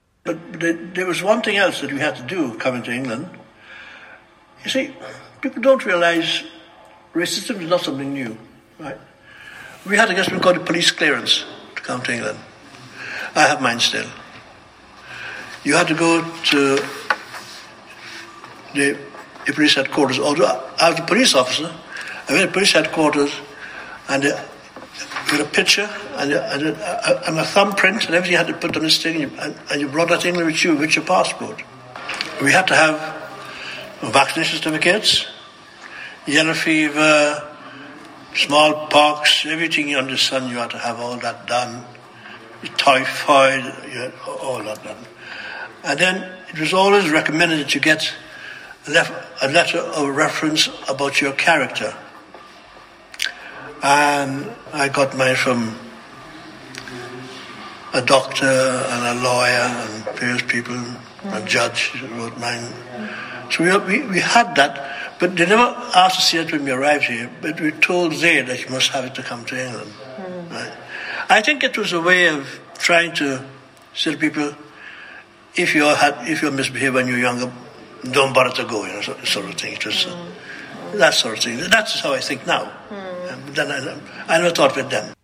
interviewee
This oral history excerpt has been drawn from the scoping project ‘Nationality, Identity and Belonging: An Oral History of the ‘Windrush Generation’ and their Relationship to the British State, 1948-2018'.